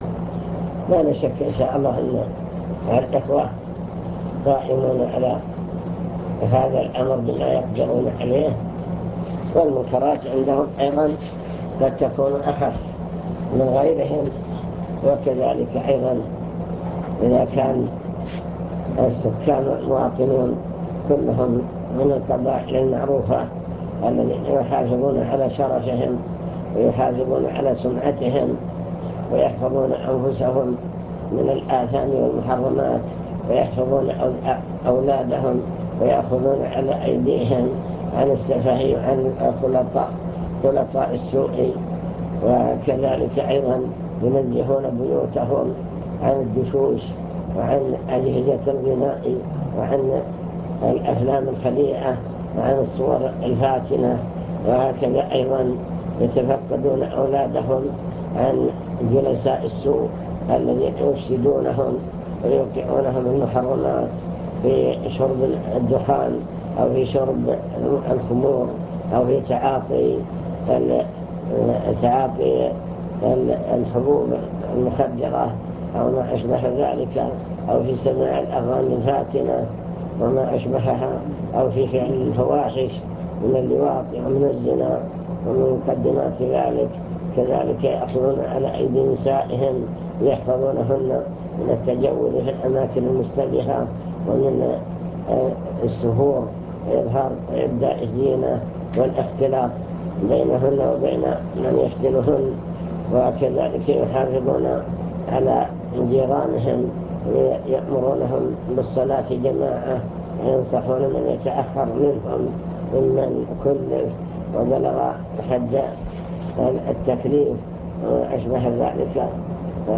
المكتبة الصوتية  تسجيلات - لقاءات  كلمة في الهيئة